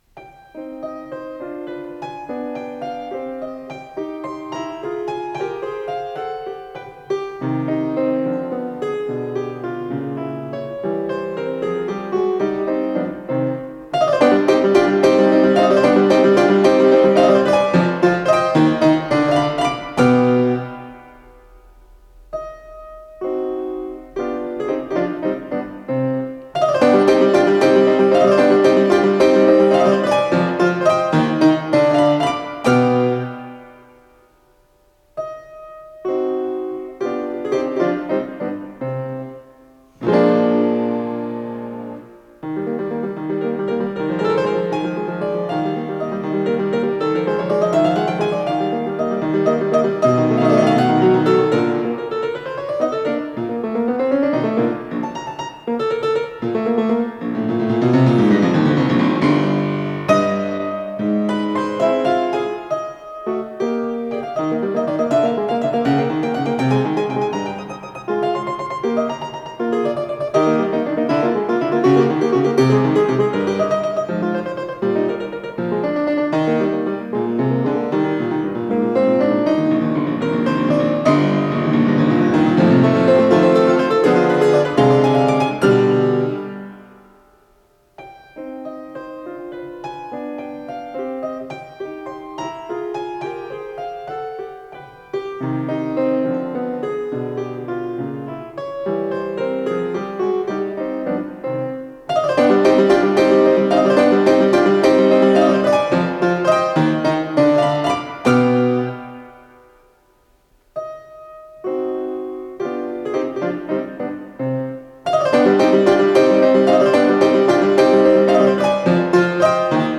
с профессиональной магнитной ленты
ПодзаголовокСоч. К 475, до минор
старинное молоточковое фортепиано
ВариантДубль моно